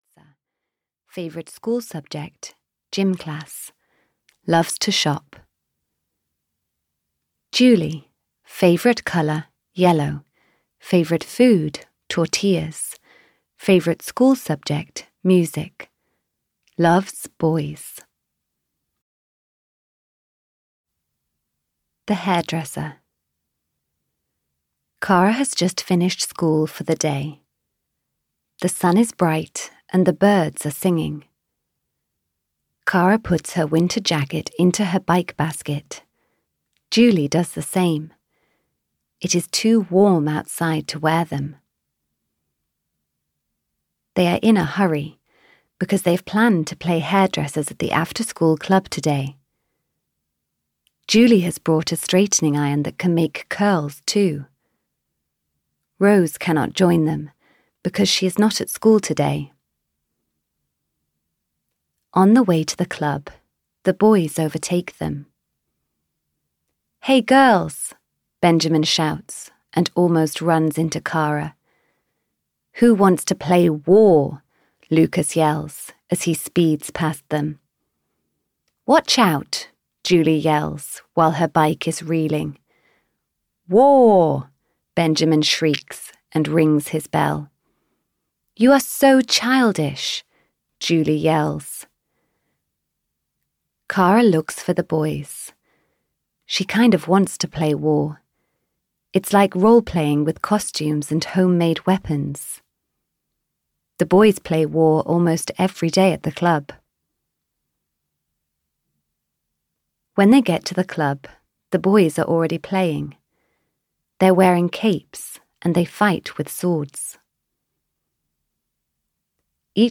K for Kara 6 - This Means War! (EN) audiokniha
Ukázka z knihy